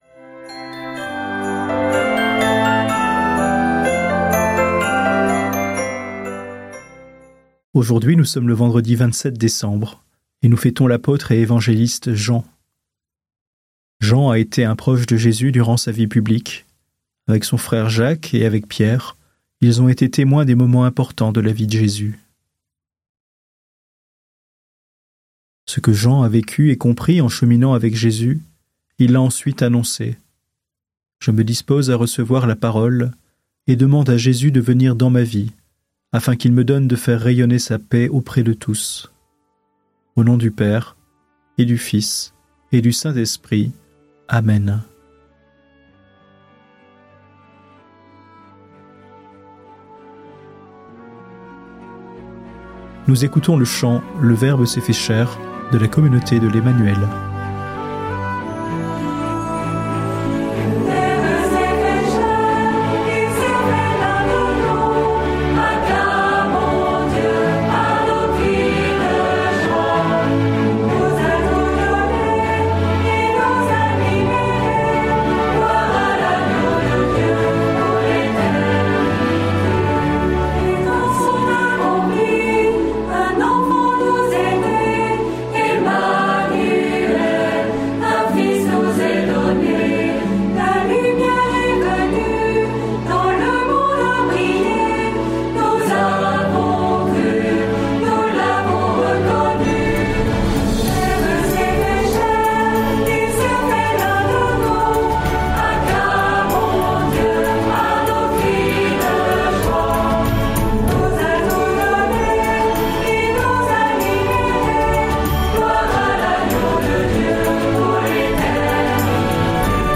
Musiques